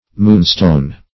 Moonstone \Moon"stone`\ (m[=oo]n"st[=o]n`), n. (Min.)